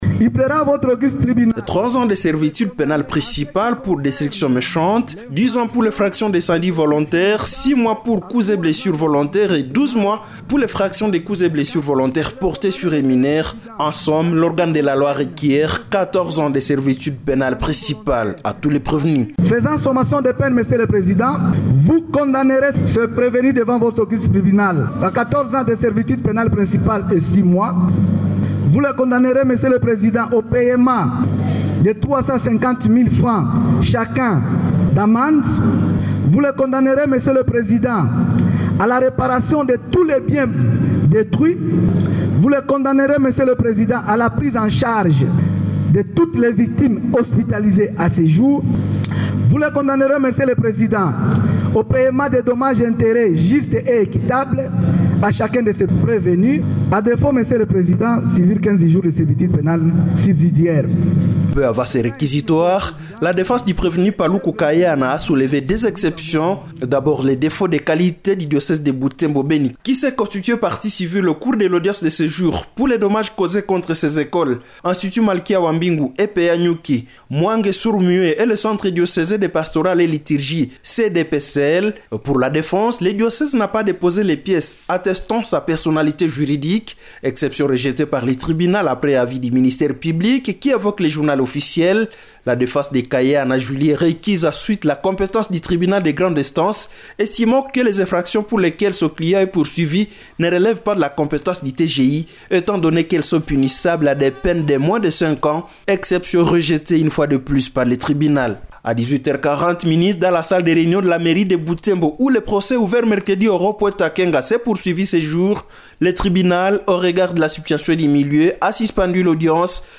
Compte rendu d’audience